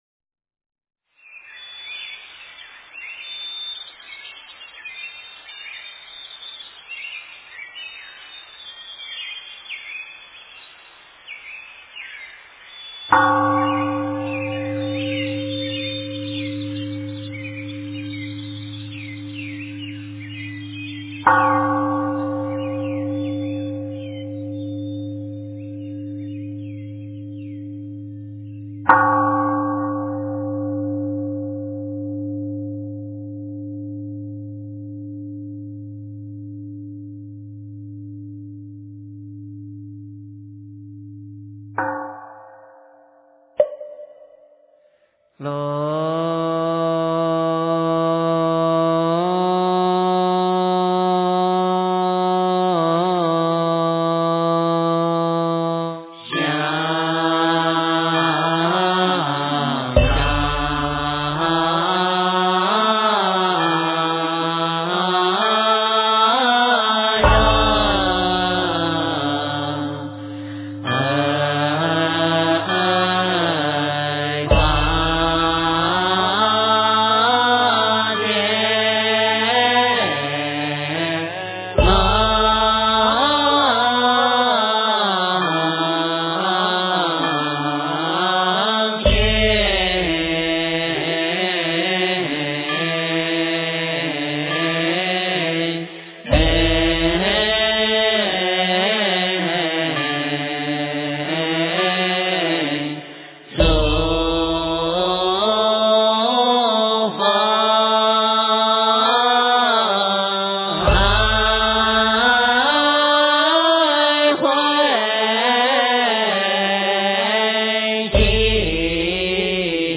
经忏
佛音 经忏 佛教音乐 返回列表 上一篇： 超度仪轨--僧团 下一篇： 阿弥陀佛大乐心咒--海涛法师 相关文章 药师佛圣号.念诵--普寿寺 药师佛圣号.念诵--普寿寺...